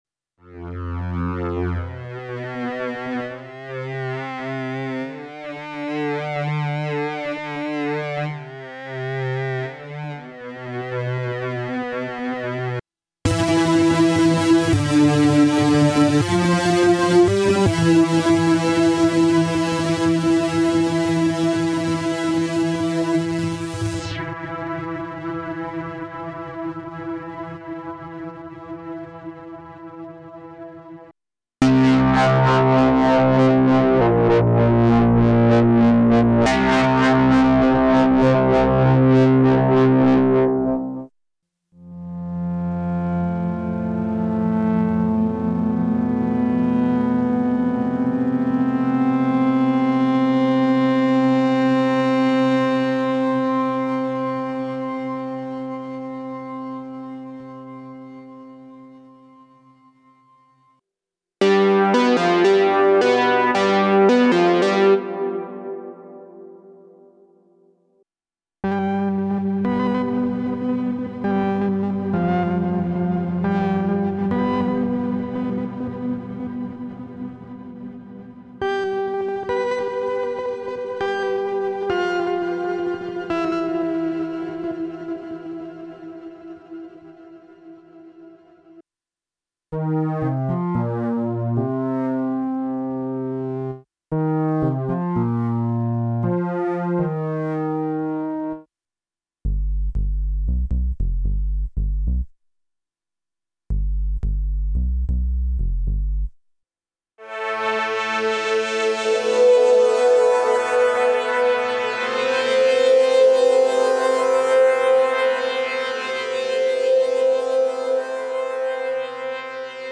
Here’s a Digitakt 2 track where all synth sounds are sampled from Artemis.
There are some chopped cc modulated bass sounds as well as longer Euclidean sequences with buildup / breakdown parts baked into the samples: